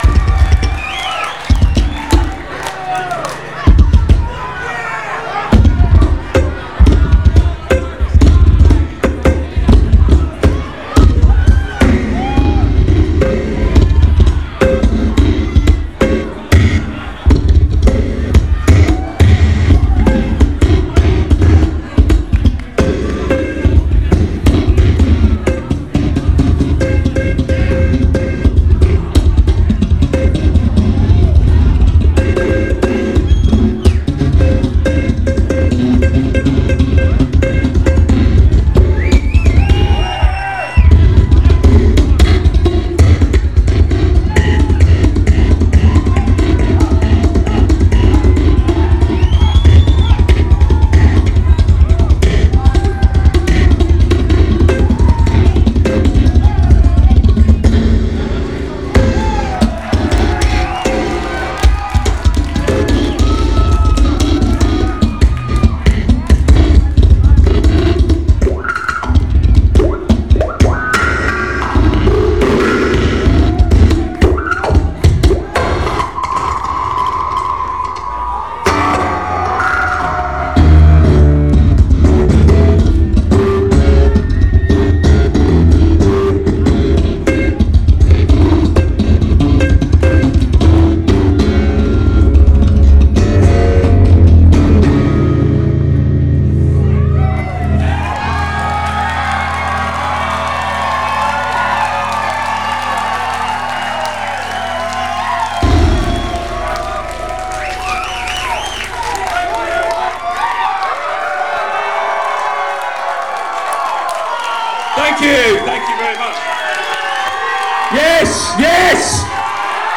Drill & bass